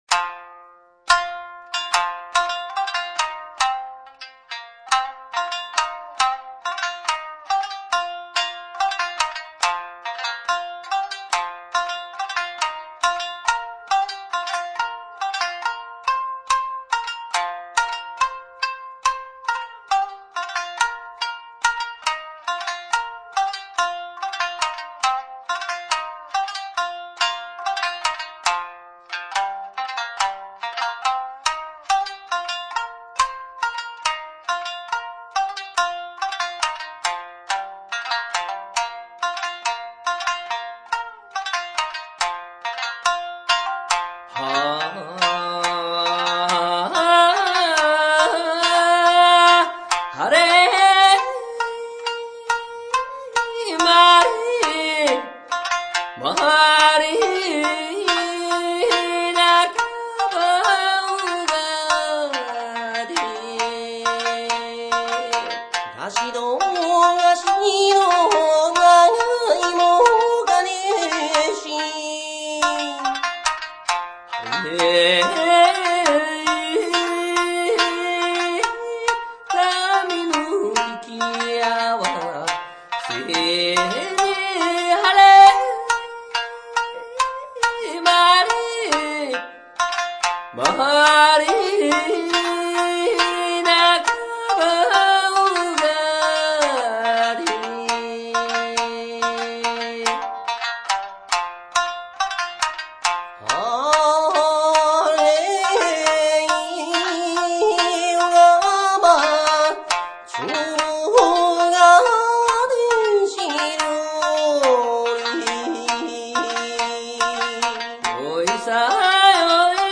シマ唄ユニット"マブリ"
唄、三味線、ギター
唄、ジャンベ、チヂン